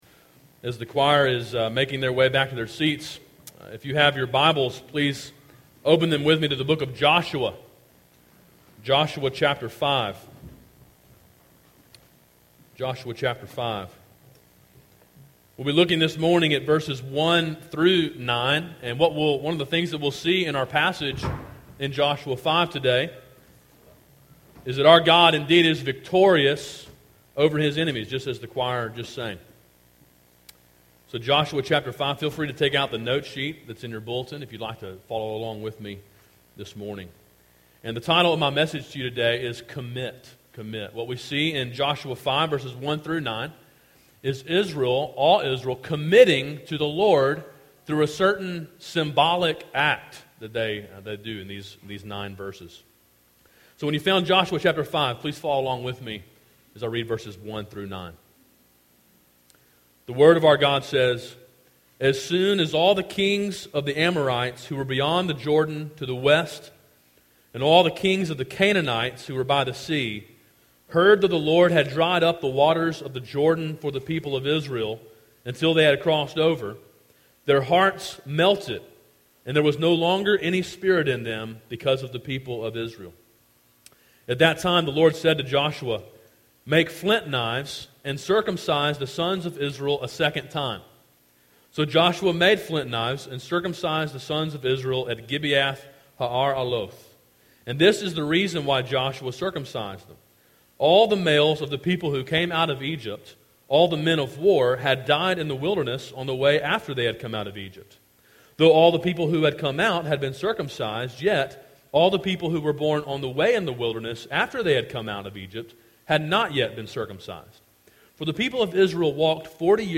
Sermon Audio: “Commit” (Joshua 5:1-9) – Calvary Baptist Church
A sermon in a series on the book of Joshua.